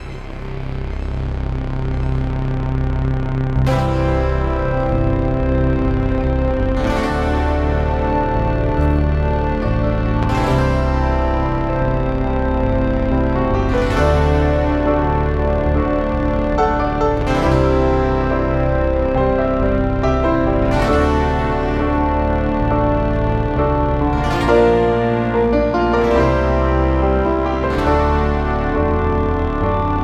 Percussion, Sound FX
Piano